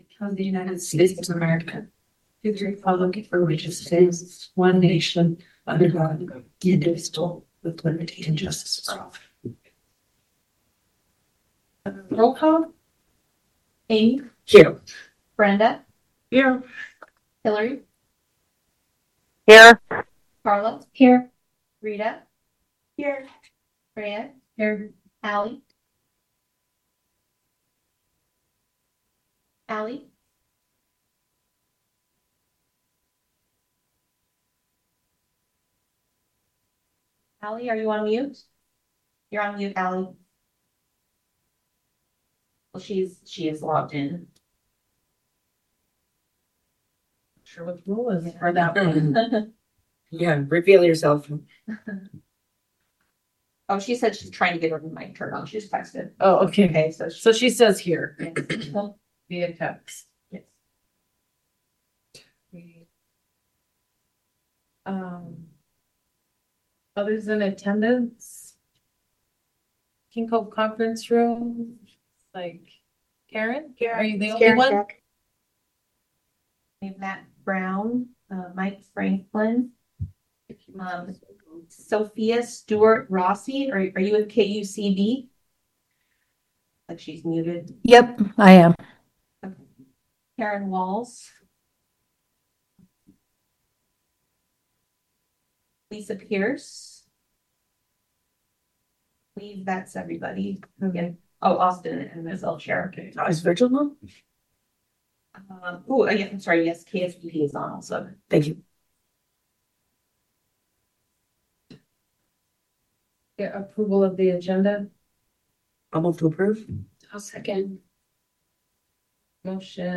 MP3+PDF: Special Meeting of the AEBSD School Board at 6:30PM on Wednesday, March 4, 2026
This meeting is open to the public at the District Office in Sand Point, borough schools & via Zoom.